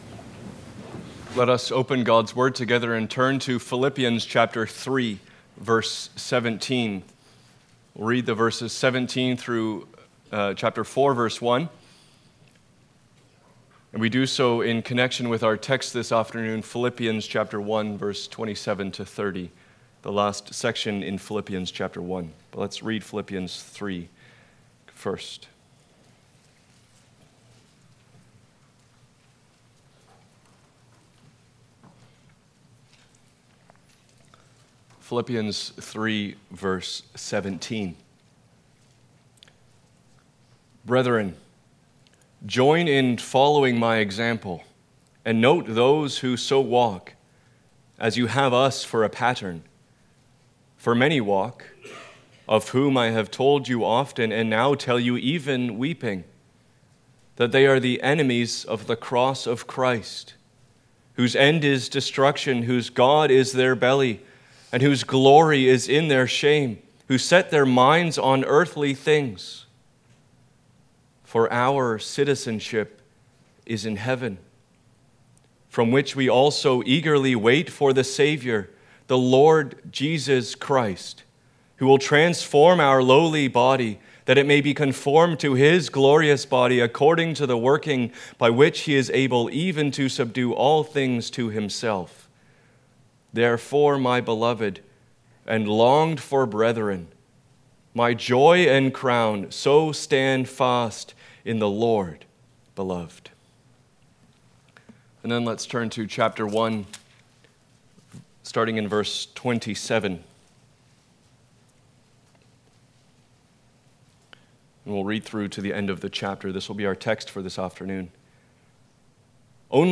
Passage: Philippians 1:27-30 Service Type: Sunday Afternoon